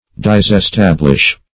\Dis`es*tab"lish\